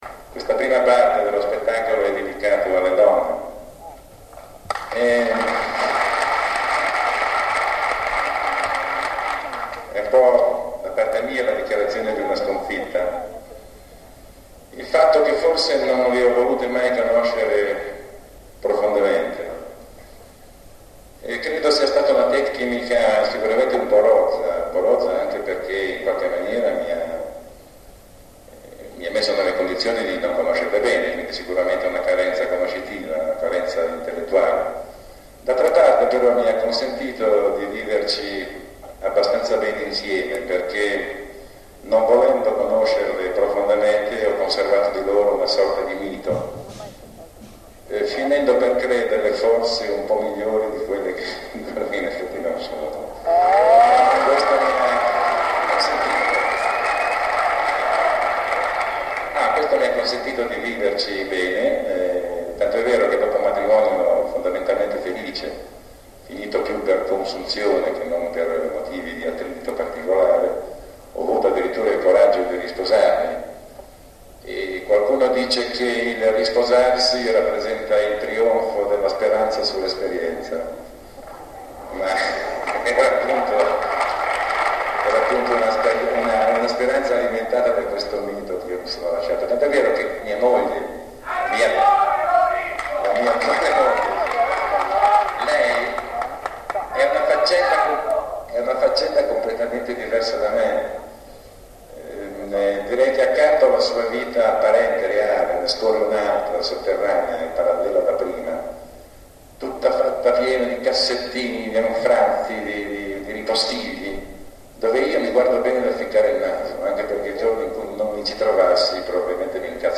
chitarra, bouzouki
batteria
violino, chitarra, liuti
piano e tastiere
basso
percussioni
fiati
• Teatro Comunale di Alessandria